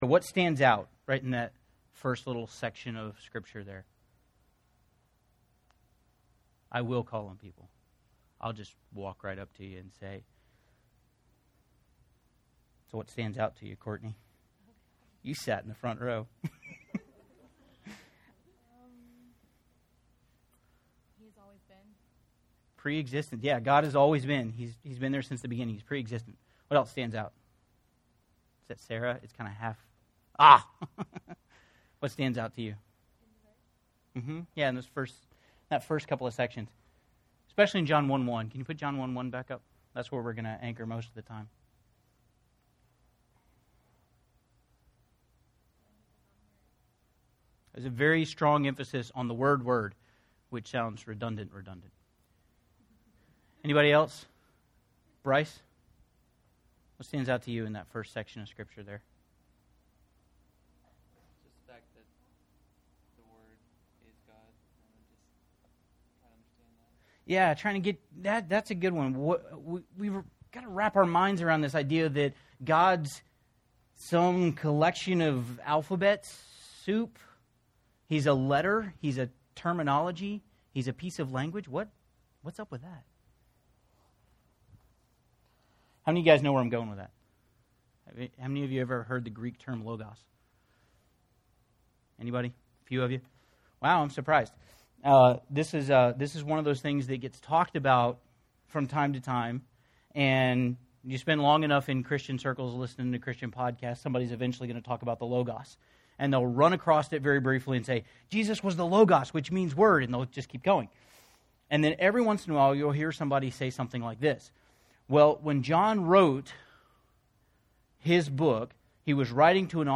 This is the audio from a 2014 talk